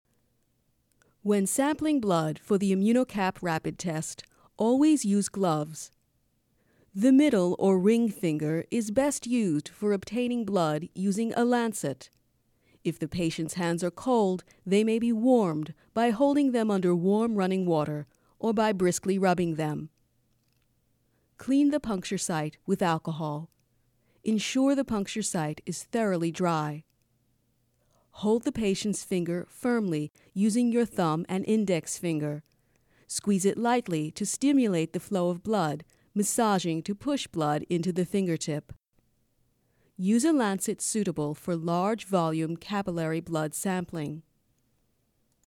Real enough to sound natural yet trained enough to sound professional.
Versatile, friendly and elegant.
Sprechprobe: eLearning (Muttersprache):
My work always features a neutral accent, clear pronunciation and natural expression.